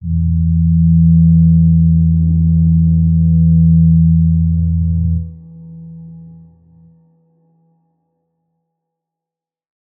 G_Crystal-E3-f.wav